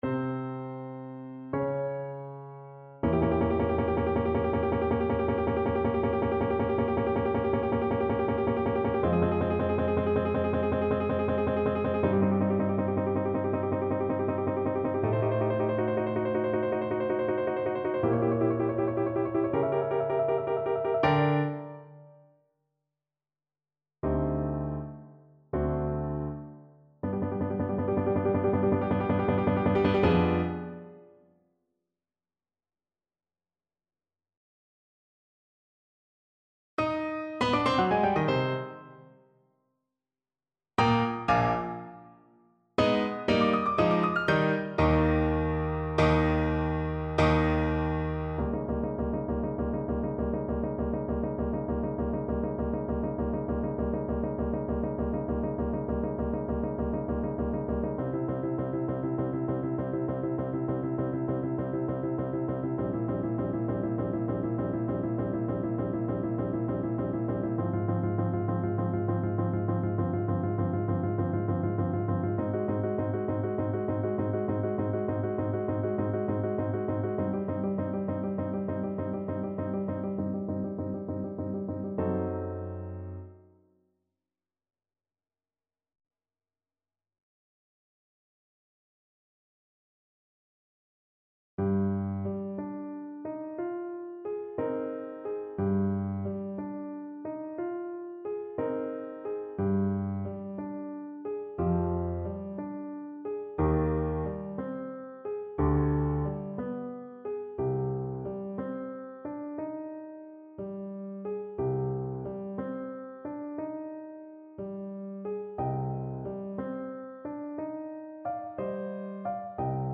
Andante
4/4 (View more 4/4 Music)
Classical (View more Classical Baritone Voice Music)